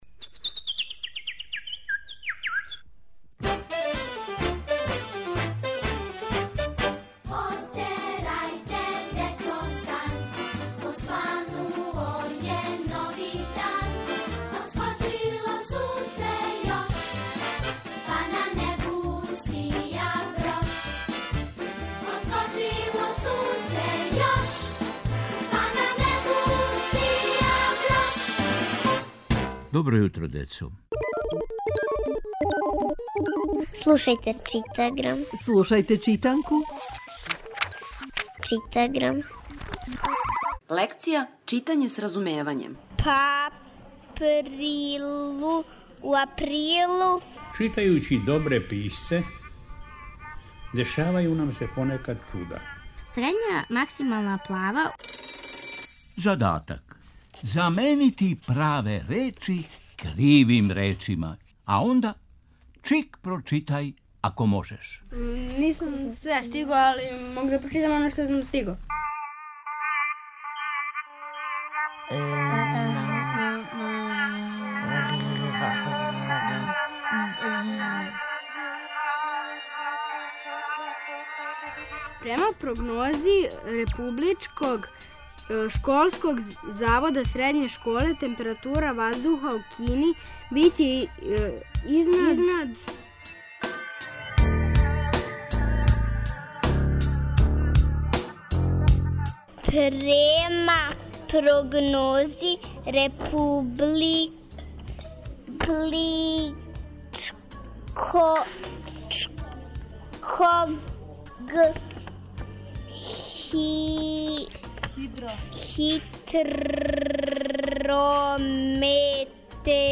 Читаграм је читанка која се слуша. Слушамо лекцију из читанке за 1. разред.